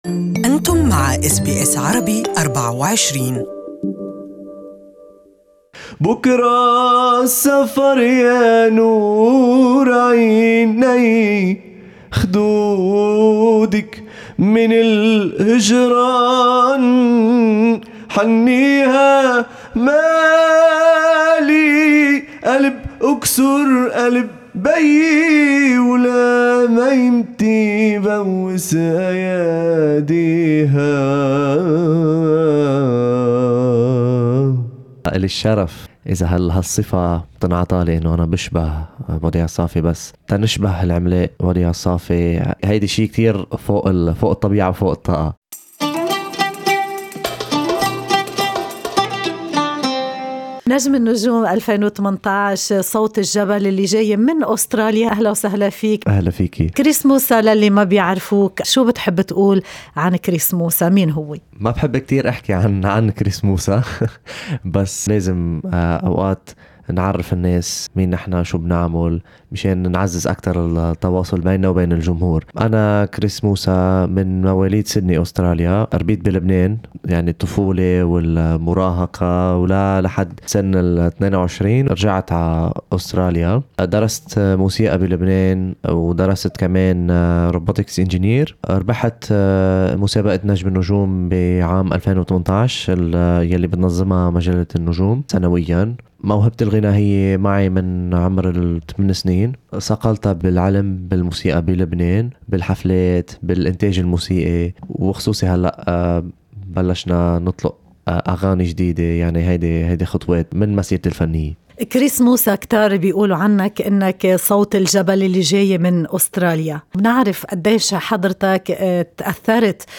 بلونها التراثي الشعبي وبآداء مميز يجمع الصوت القوي والموال التراثي